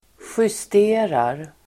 Uttal: [sjyst'e:rar]
justerar.mp3